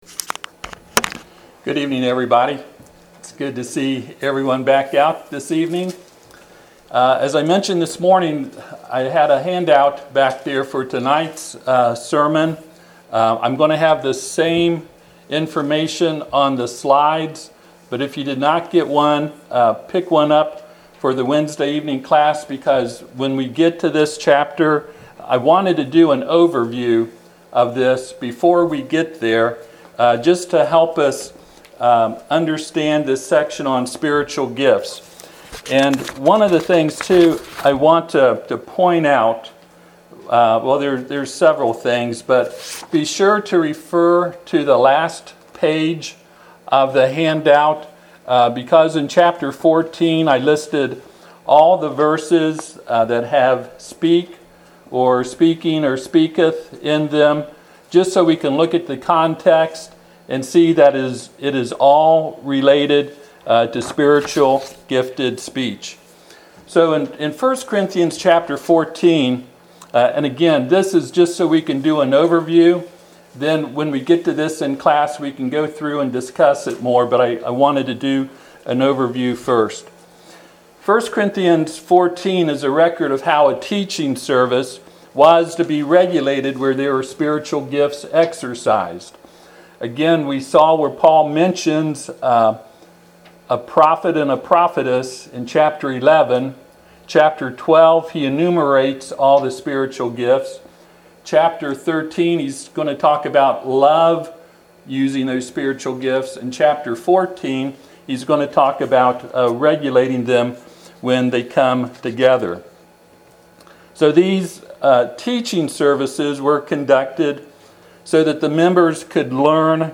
Passage: 1 Corinthians 14 Service Type: Sunday PM « Sermon on the Mount